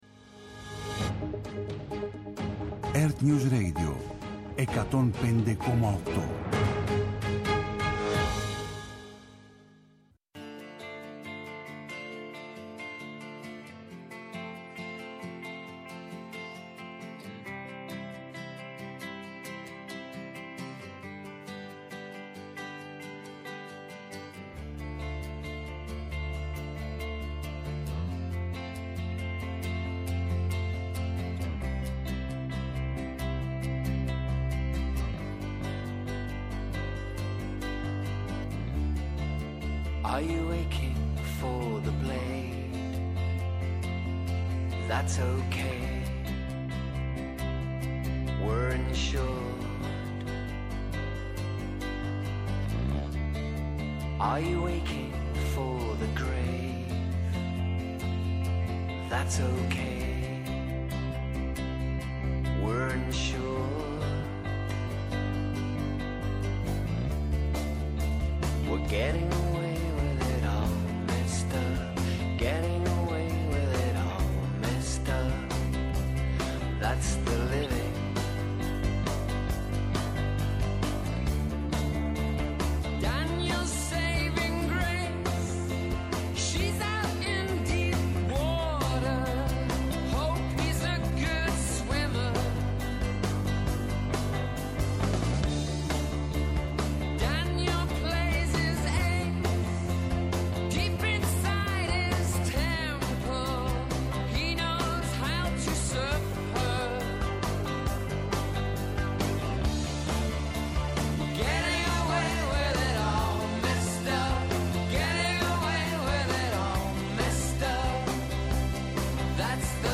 Σύνδεση με ERTNEWS για να παρακολουθήσουμε τη δευτερολογία του προέδρου του ΠΑΣΟΚ Νίκου Ανδρουλάκη και στη συνέχεια την τριτολογία του Πρωθυπουργού Κυριάκου Μητσοτάκη στην συζήτηση για το κράτος δικαίου στη Βουλή